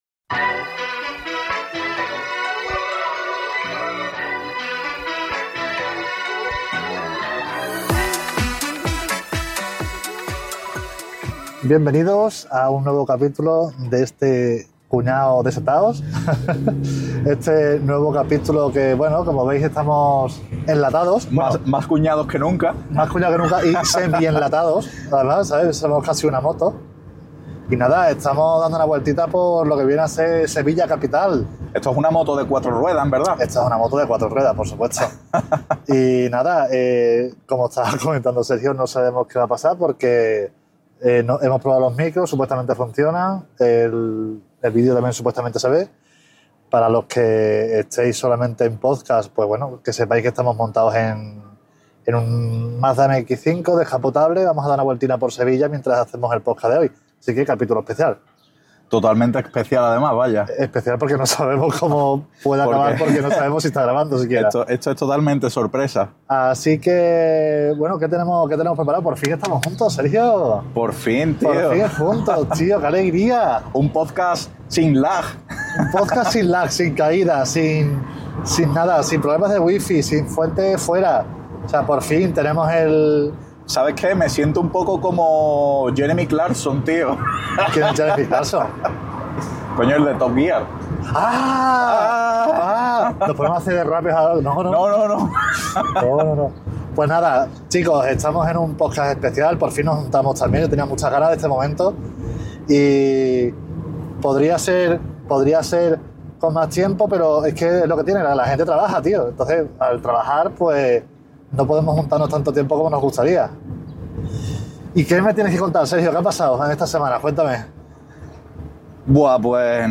Especial podcast en un Mazda MX-5 [T1E5] – Boxassriders